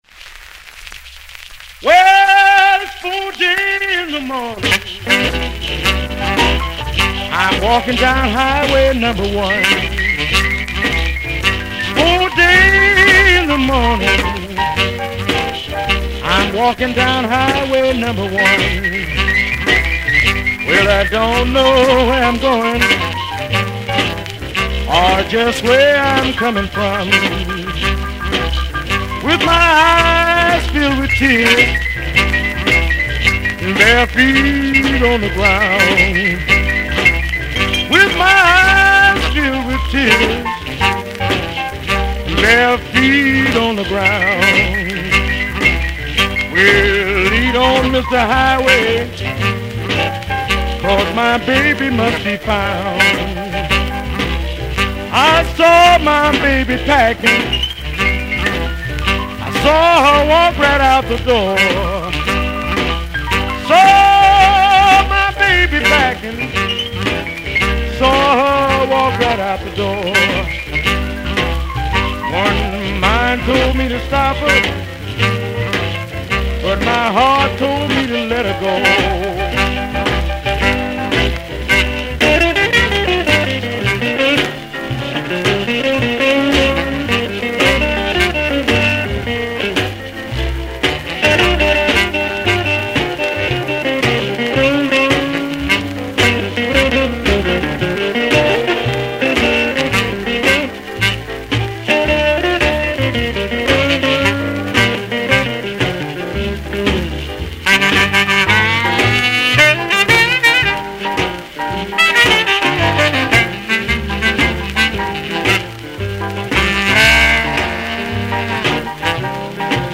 R&B
vocal
tenor sax